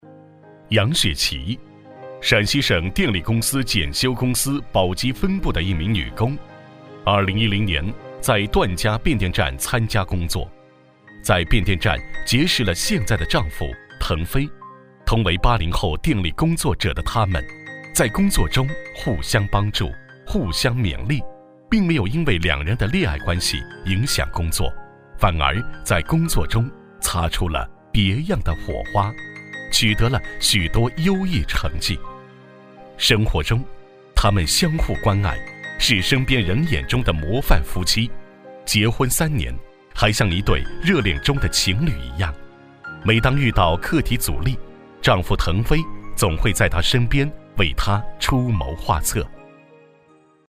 人物讲述男243号
稳重磁性 人物专题
磁性男中音，大气激情，磁性稳重。